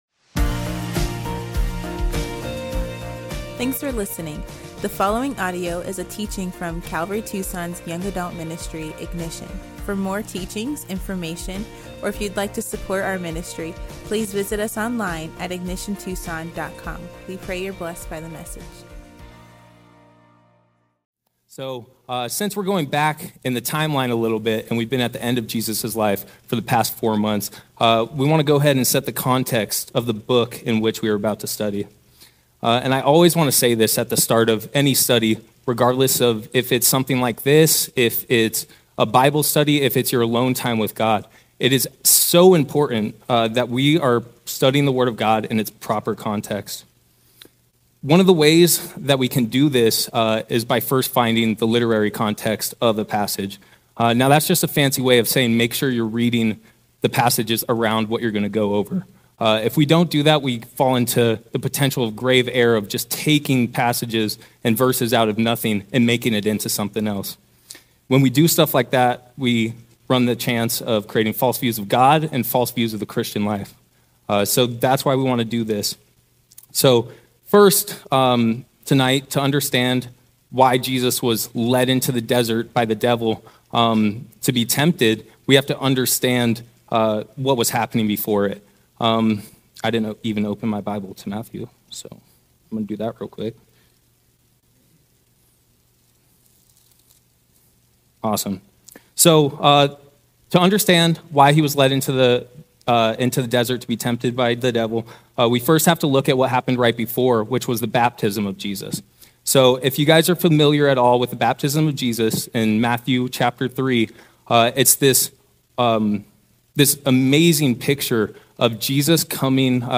Ignition: A Heart Full of Scripture | Guest Teaching - Calvary Tucson Church